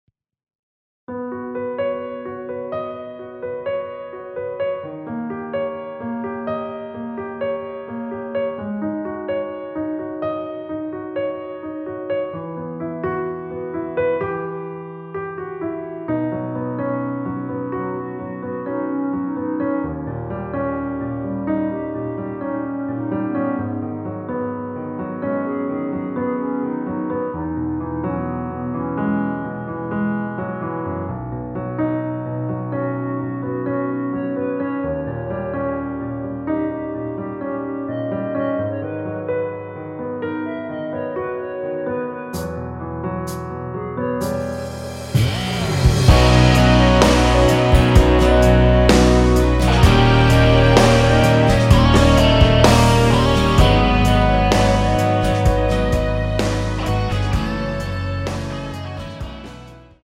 원키에서(-3)내린 (1절앞+후렴)으로 진행되게 편곡한 멜로디 포함된 MR입니다.
앞부분30초, 뒷부분30초씩 편집해서 올려 드리고 있습니다.